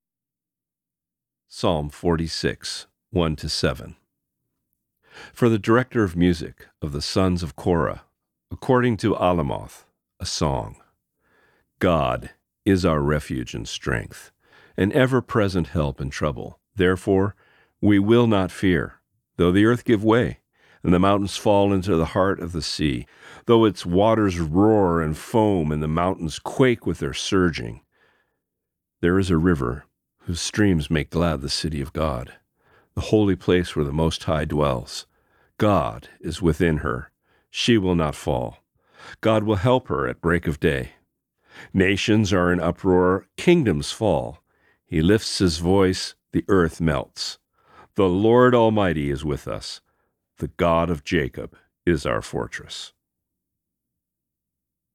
Reading: Psalm 46:1-7